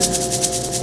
Shaker FX 03.wav